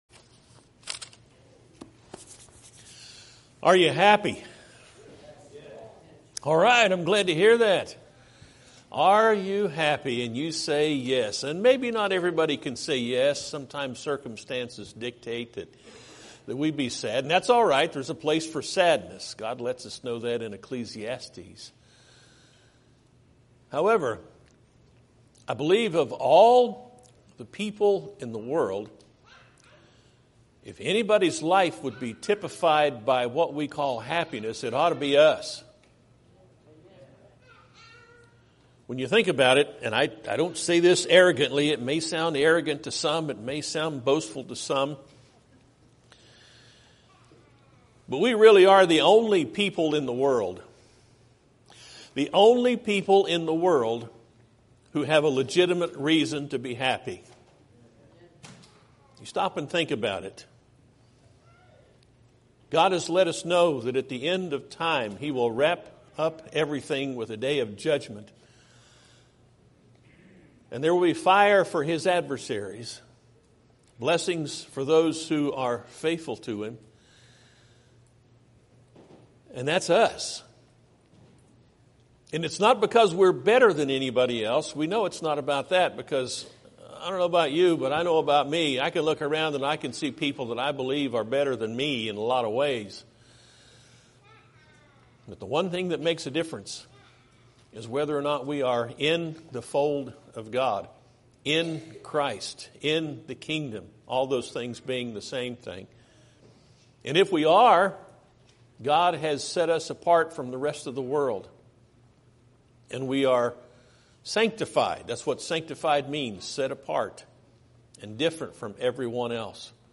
Podcasts Videos Series Sermons Are You Happy?